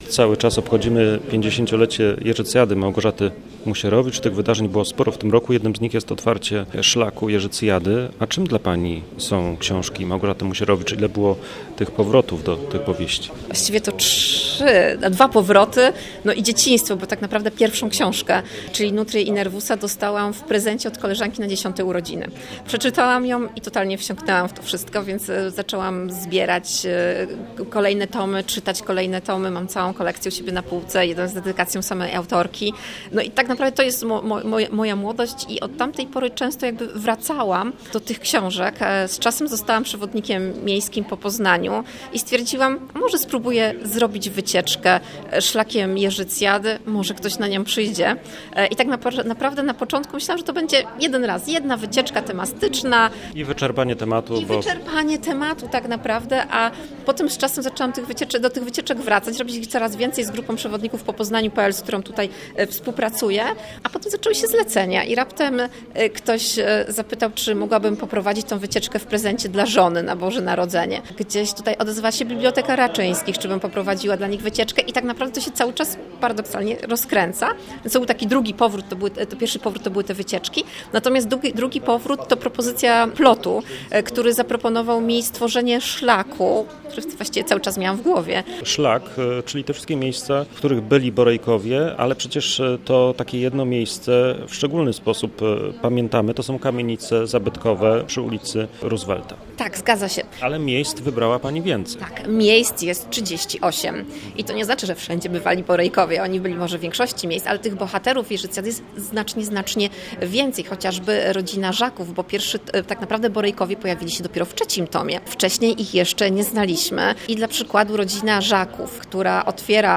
Zapis rozmowy: